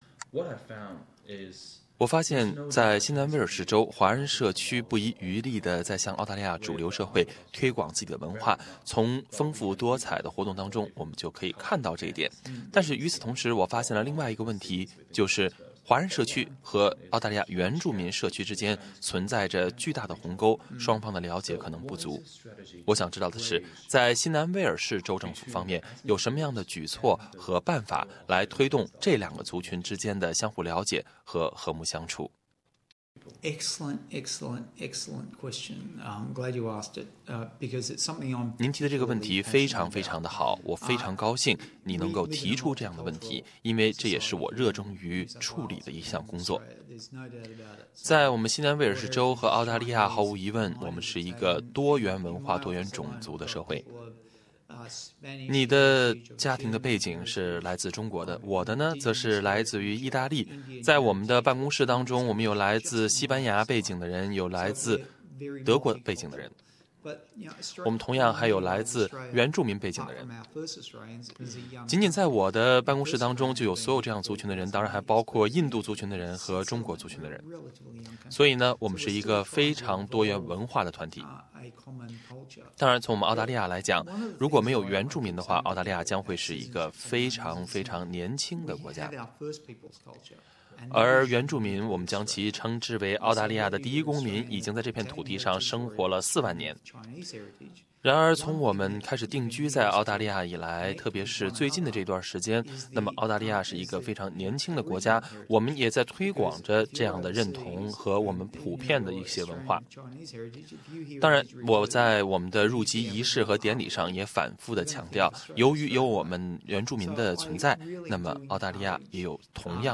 对话新州原住民事务部长维克多·多米尼洛